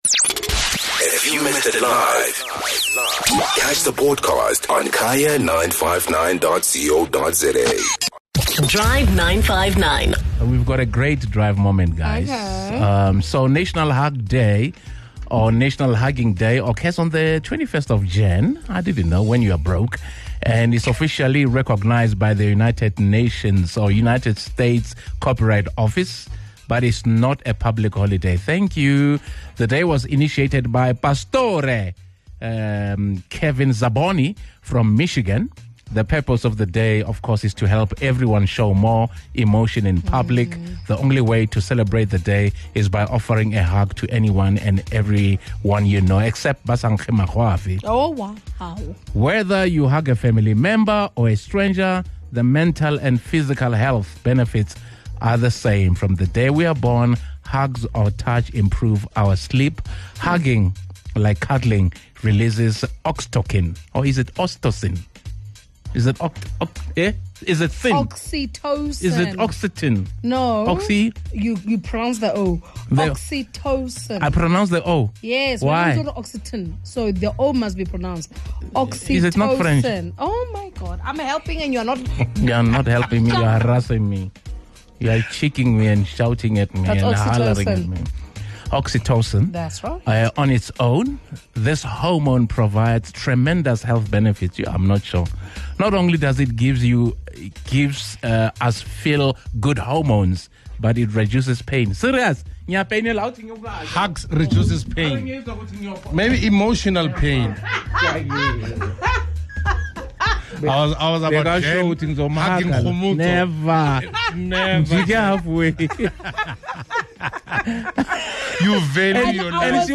Take a listen to some very interesting hug requests, that the Drive 959 team heard from listeners.